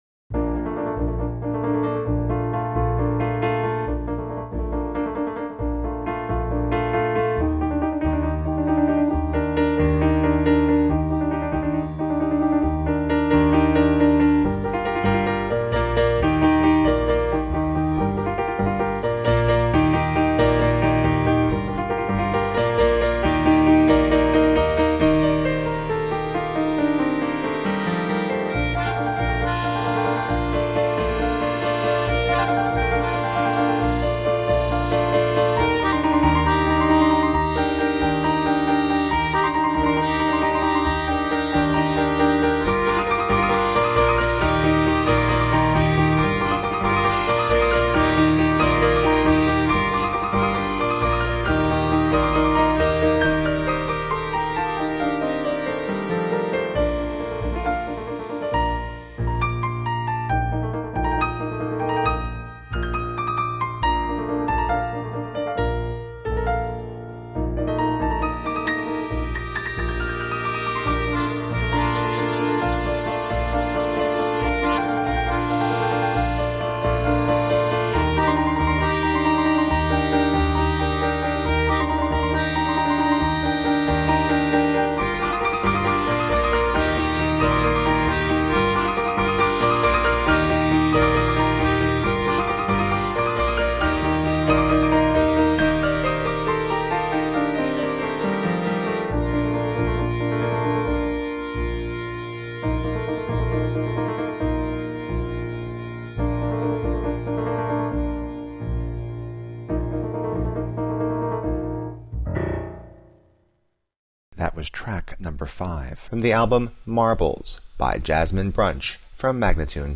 A secret garden of lucid musical dreams .
Tagged as: New Age, Instrumental New Age, Contemporary Piano